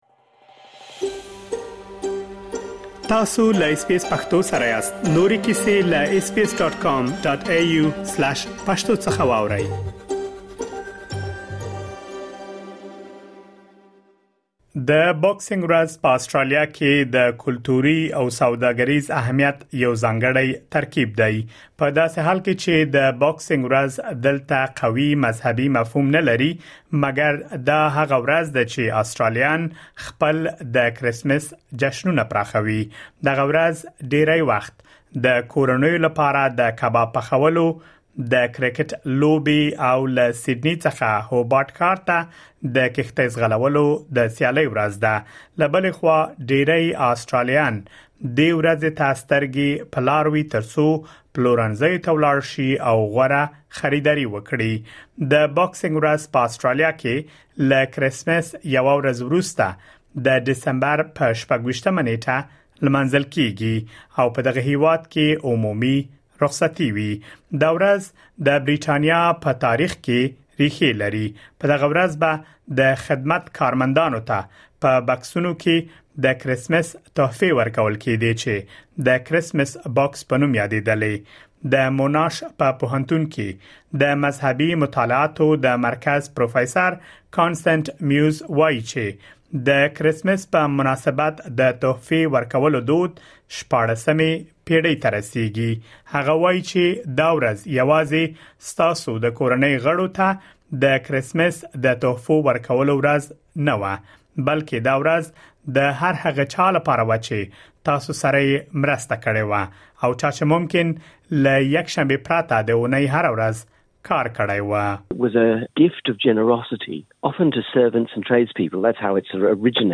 د دغې ورځې په اړه مهم معلومات په رپوټ کې اورېدلی شئ.